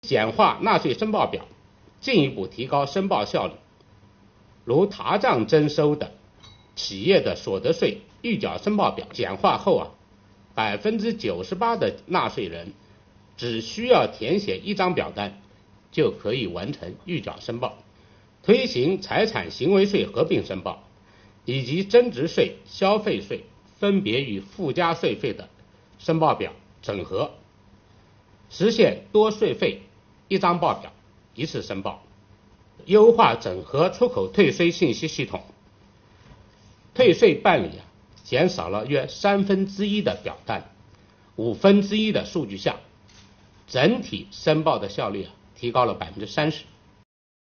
近日，国家税务总局召开新闻发布会，就税收大数据反映经济发展情况、税务部门学党史办实事扎实推进办税缴费便利化、打击涉税违法犯罪等内容进行发布并回答记者提问。会上，国家税务总局纳税服务司司长韩国荣介绍了“我为纳税人缴费人办实事暨便民办税春风行动”取得的进展和成效。